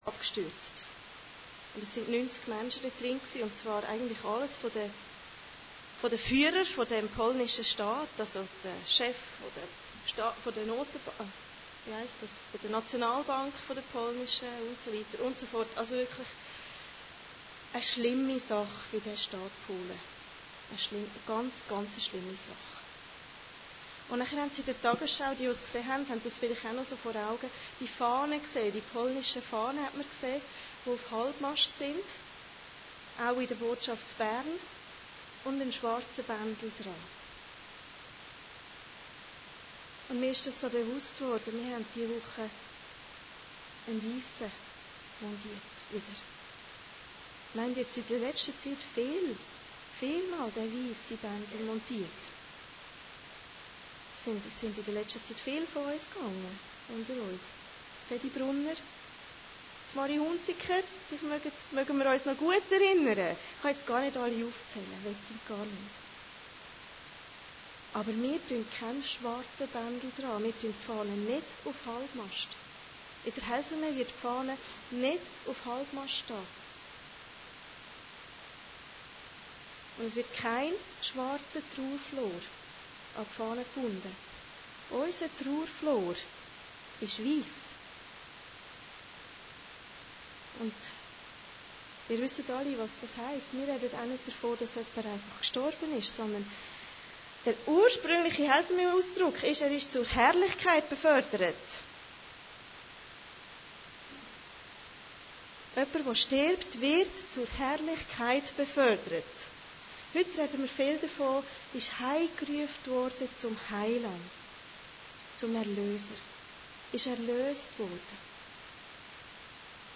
Predigten Heilsarmee Aargau Süd – Sendschreiben an Smyrna